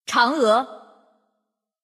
王者荣耀_人物播报_嫦娥.mp3